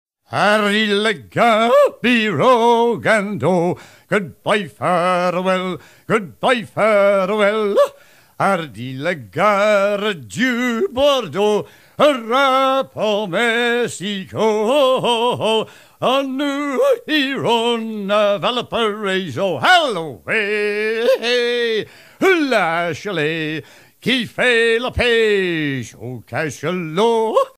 Fonction d'après l'analyste gestuel : à hisser à grands coups
Genre laisse
Catégorie Pièce musicale éditée